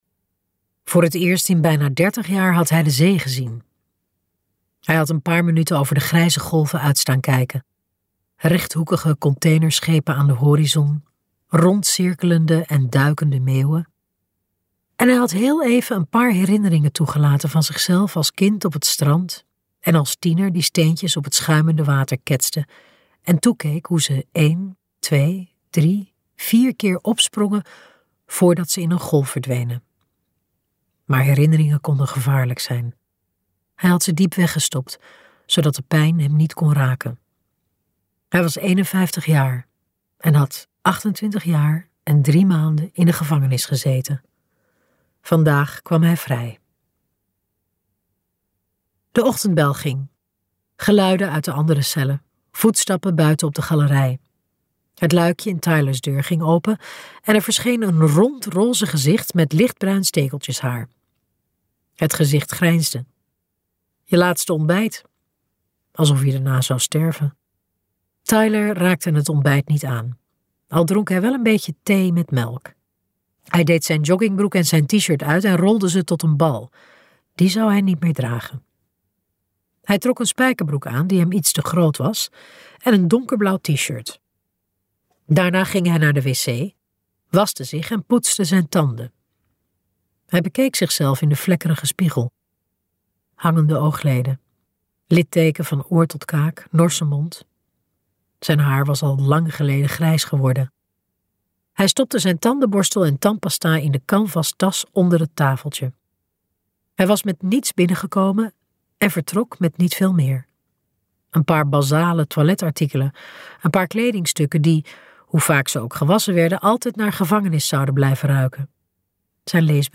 Ambo|Anthos uitgevers - Tyler green komt nooit meer vrij luisterboek